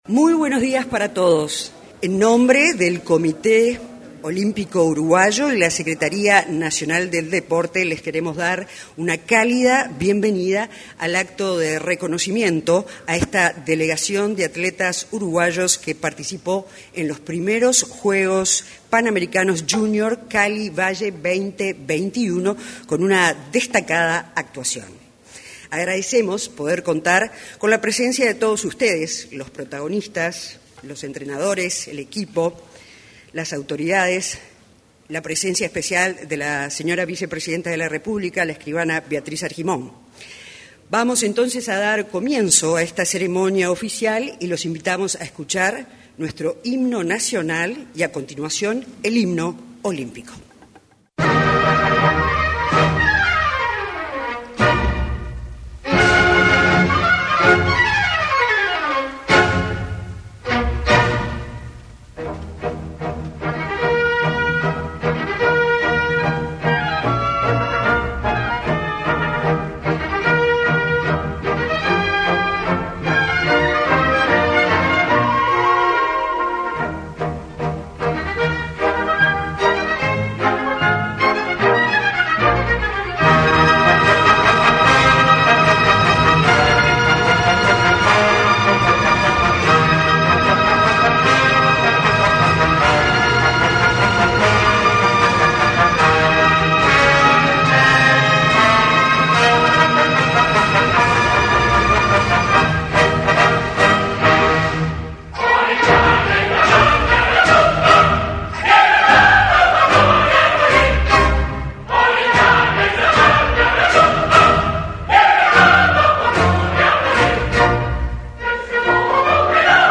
Este viernes 10 en el auditorio de Torre Ejecutiva, se realizó el recibimiento de los atletas que regresaron de los Juegos Panamericanos Junior Cali.
Se expresaron el secretario nacional del Deporte, Sebastián Bauzá; el presidente del Comité Olímpico Uruguayo, Julio César Maglione; y Dolores Moreira.